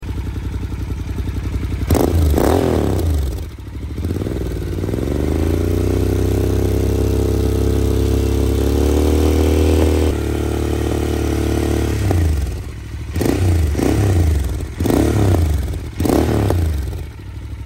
Venom Exhaust with dB Killer
• Sporty note with deep bass